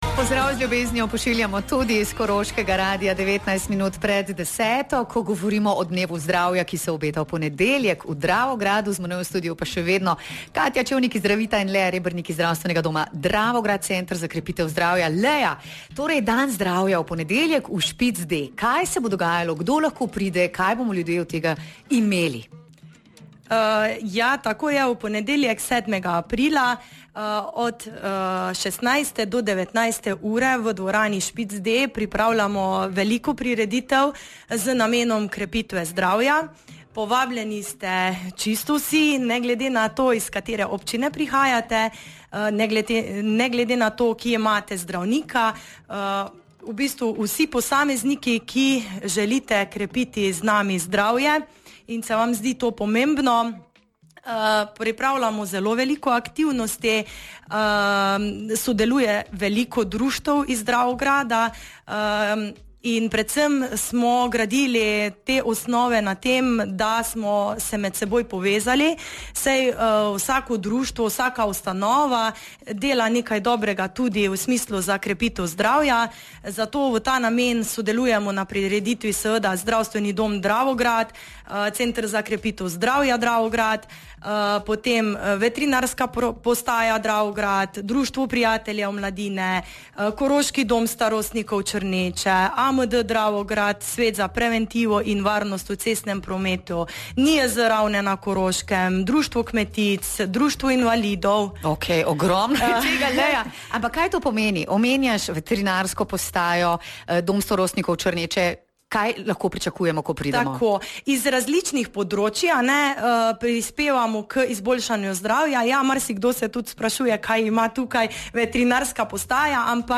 Več v pogovoru